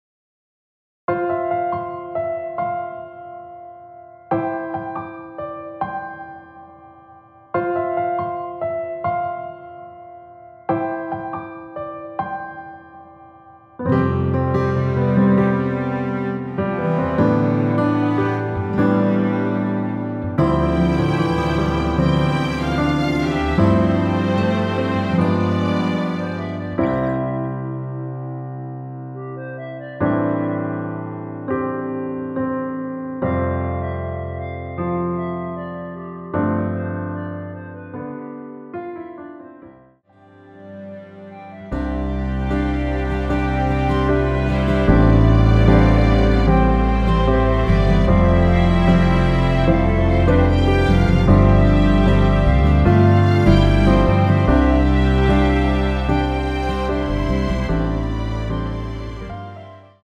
이곡은 프리템포라 앞부분 멜로디 소리가 늘어지게 들릴수도 있으나
원곡과 똑같은 템포로 제작하였으니 미리듣기 확인후 참고하여 이용하여주세요.
원키에서(-1)내린 멜로디 포함된 MR입니다.
앞부분30초, 뒷부분30초씩 편집해서 올려 드리고 있습니다.
중간에 음이 끈어지고 다시 나오는 이유는